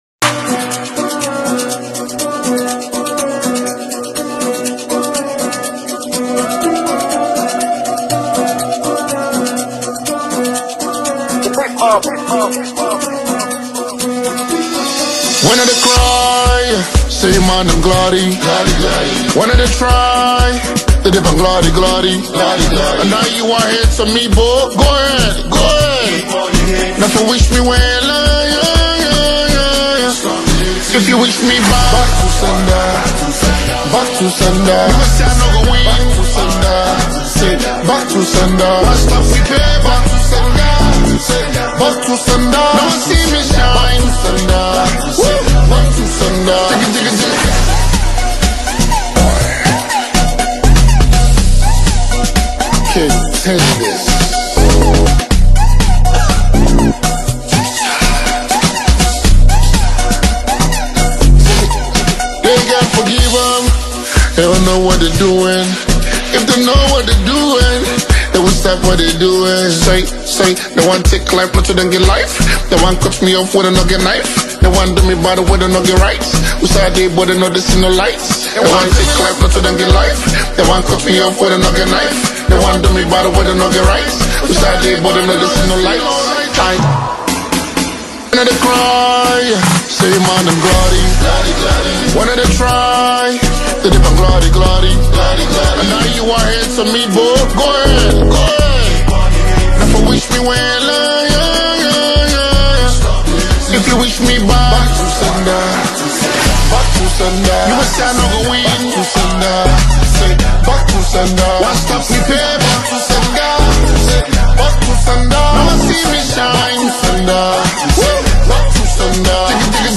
is a mixture of afrobeats and rap
With a melodious hook and chorus